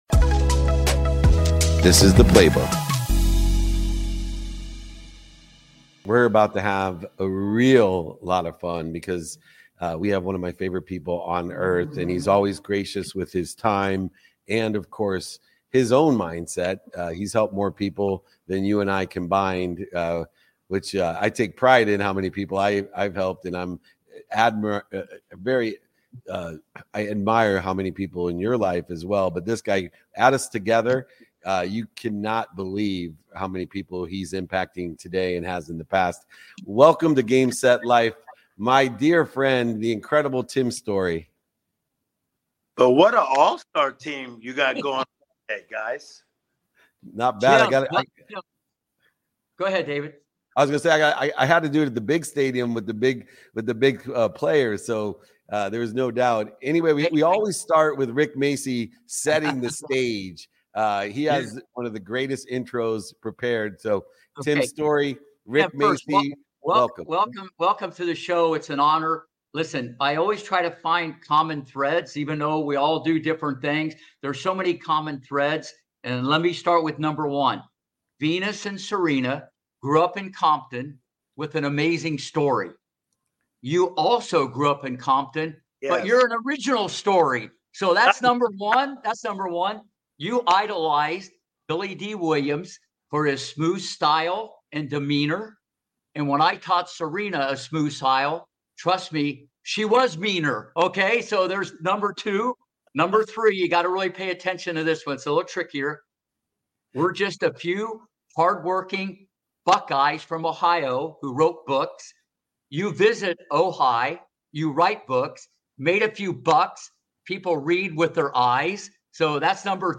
During our powerful conversation, we explored into the transformative power of living in the miracle mentality, the #1 mindset to utilize in growing a stronger faith, and why we’re each gifted with a mandate to empower and elevate those around us.